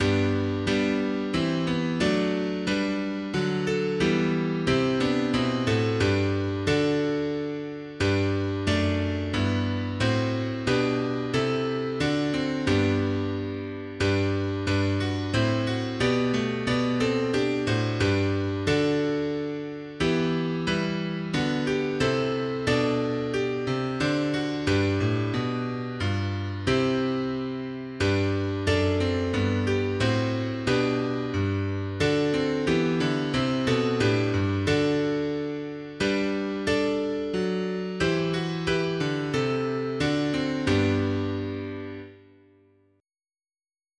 Language: German Instruments: A cappella